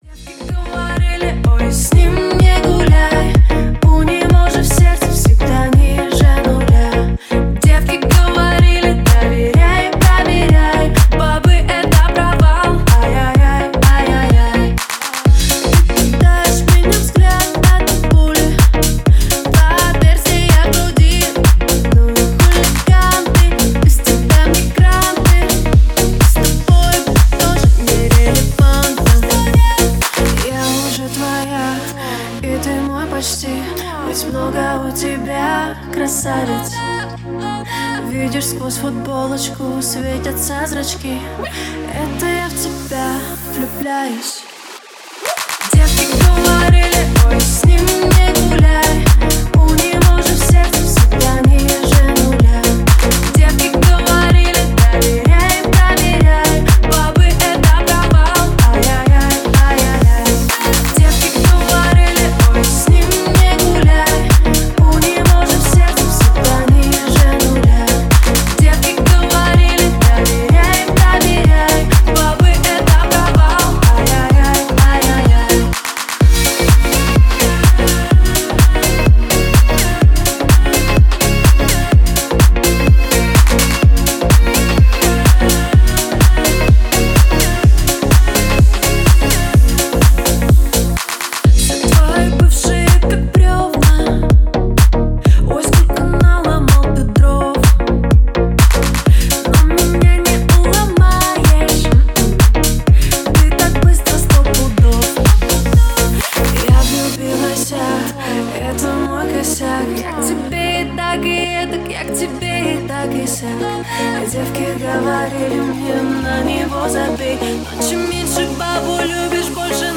это энергичный трек в жанре поп и EDM
звучание становится более динамичным и танцевальным